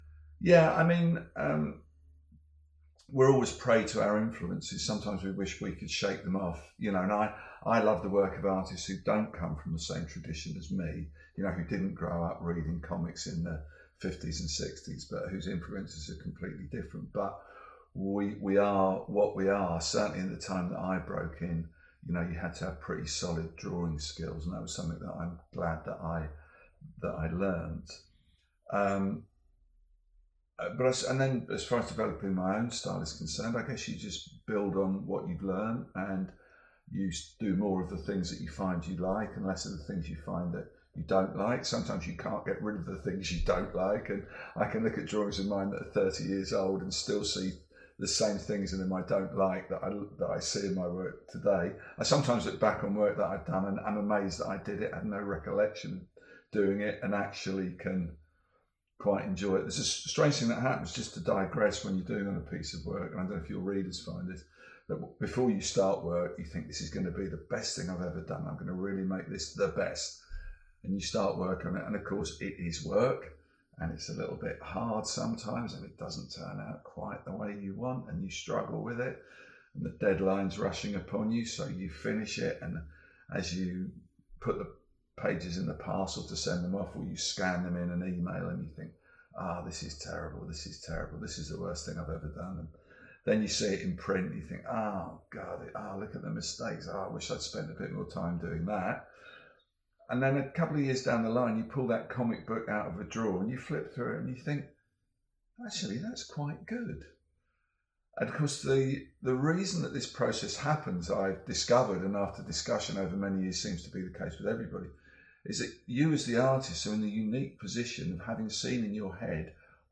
Dave Gibbons interview: How did you develop your own style?